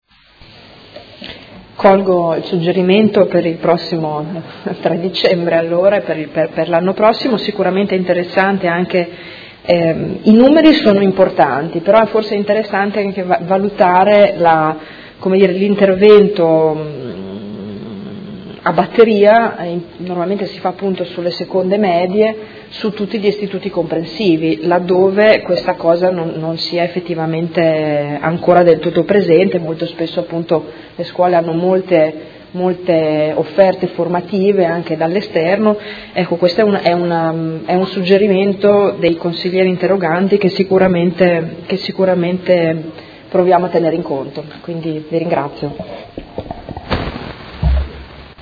Giuliana Urbelli — Sito Audio Consiglio Comunale
Seduta del 16/03/2017. Conclude dibattito su interrogazione del Gruppo Per Me Modena avente per oggetto: Azioni di intervento nelle scuole per la sensibilizzazione all’uso del preservativo quale strumento efficace per la prevenzione di MST